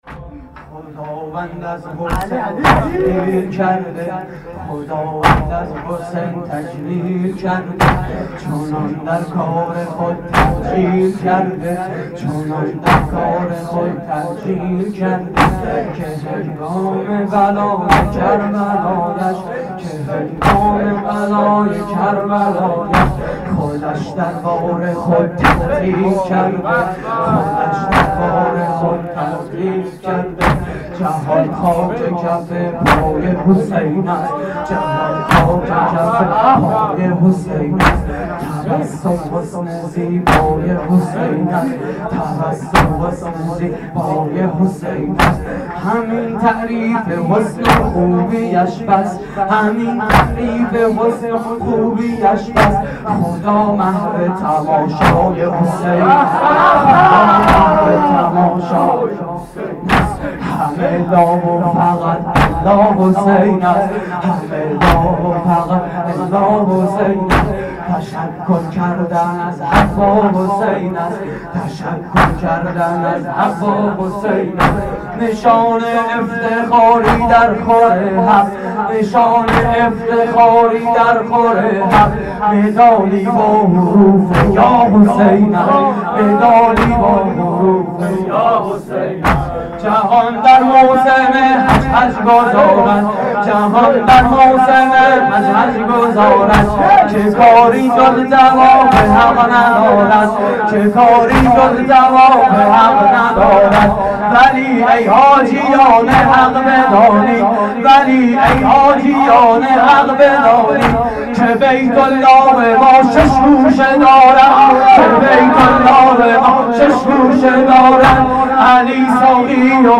واحد: خدا محو تماشای حسین است
مراسم عزاداری شب هفتم محرم 1432